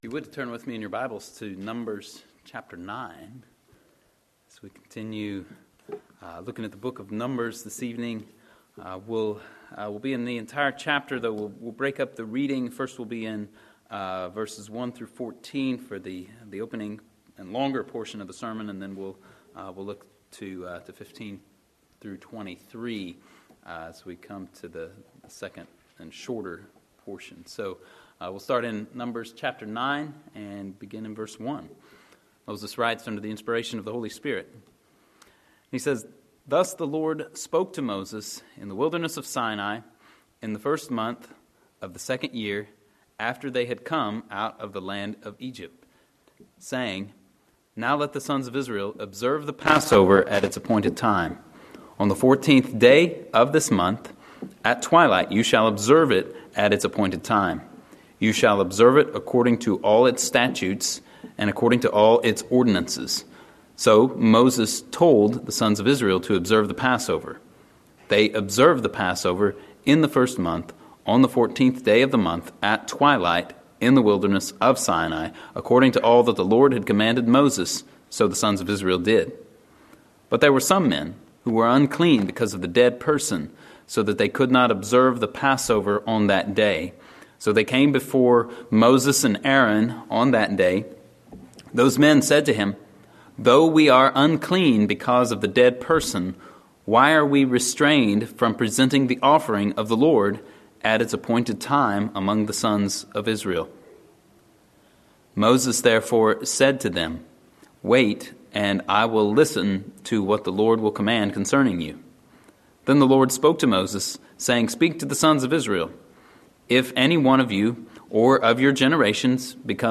Sermons from Andover Baptist Church in Linthicum, MD.